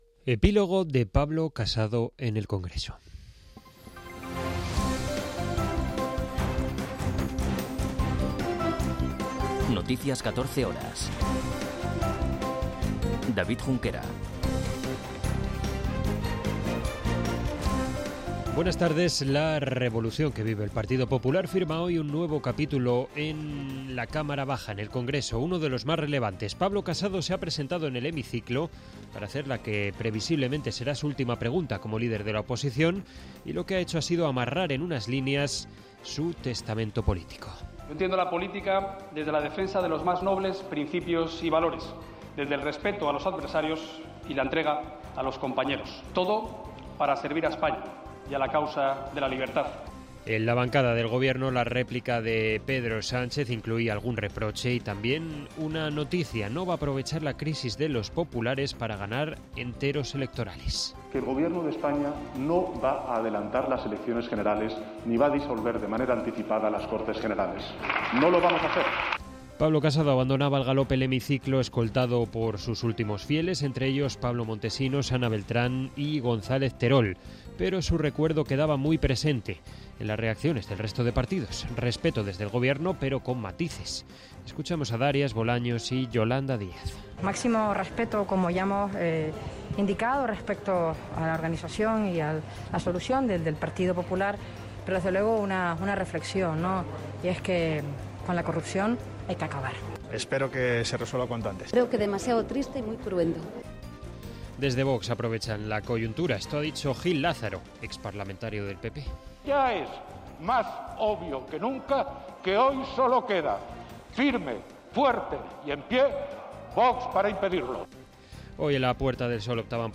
Noticias 14 horas 23.02.2022